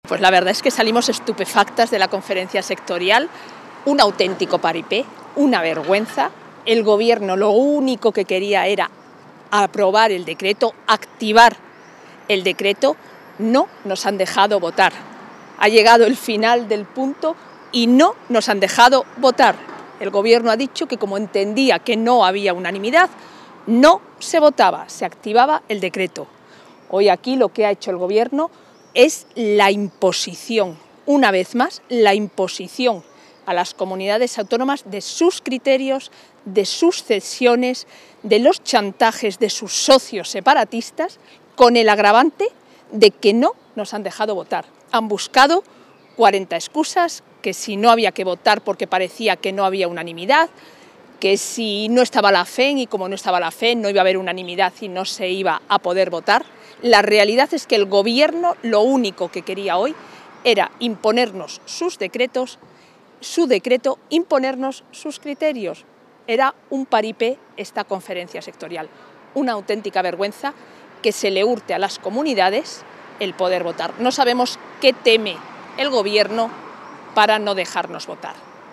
Declaraciones tras la Sectorial.